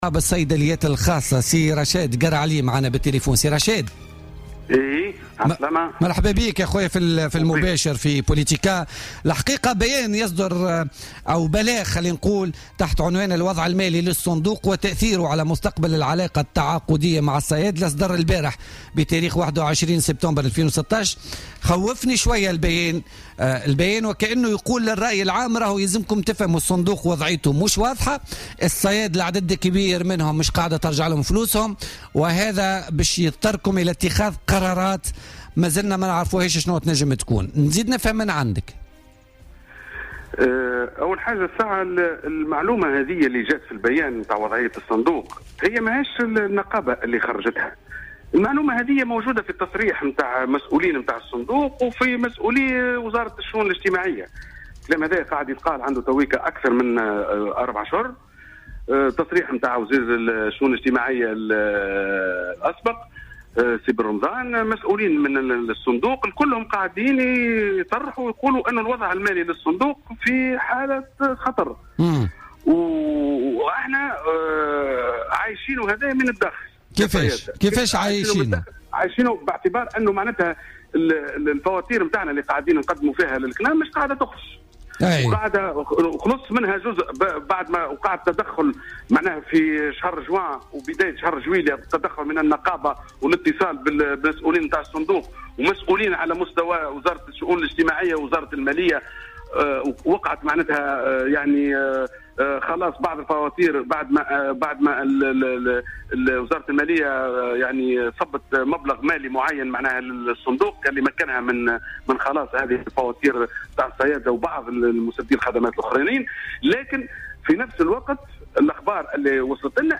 وأوضح في اتصال هاتفي مع "بوليتيكا" أن المشكل الرئيسي يكمن في عدم تسديد صندوق الضمان الاجتماعي وصندوق التقاعد والحيطة الاجتماعية مستحقات الكنام وهو ما أدى إلى عدم قدرة هذا الأخير بدوره على تسديد فواتير الصيادلة. وقال إن الوزارة المالية تدخلت لتسديد جل هذه الفواتير إلا أنه بلغهم في الآونة الآخيرة أنه ليس هناك أموالا لتسديد الفواتير الحالية. ودعا الحكومة التدخل لدفع الصندوقين على تسديد مستحقات الكنام داعيا المسؤولين المعنيين باتخاذ القرار المناسب قبل الاضطرار لتعليق الاتفاقية مع الكنام الشهر المقبل.